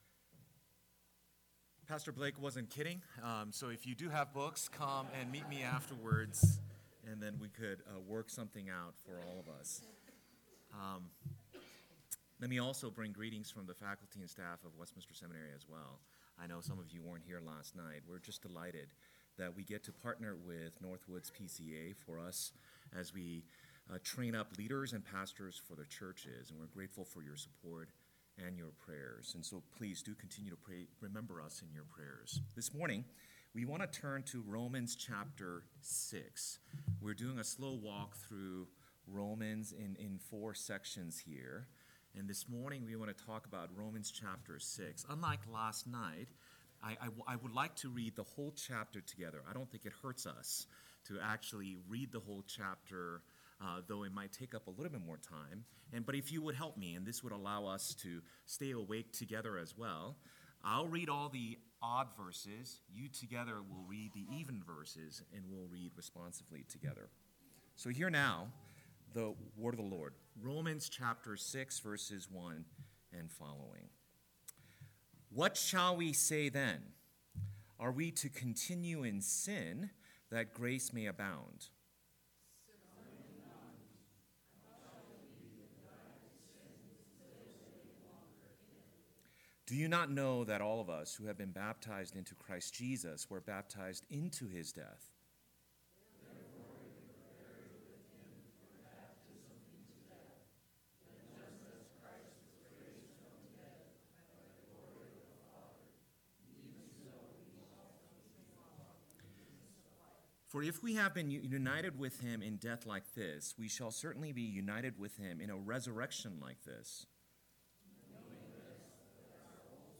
2021 Cheyenne Reformation Conference Session 2 – Northwoods Sermons